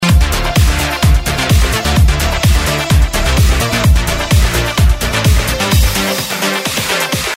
Делаю ska-punk альбом!
сцыканье *с* на 13 кило и выше, просто удар в мозг... при этом не разборчиво... попробуй прибрать самую верхушку шельфом а согласные вытащить в районе 6...10 кило ( смотри по миксу) Вложения sound.mp3 sound.mp3 119,2 KB · Просмотры: 285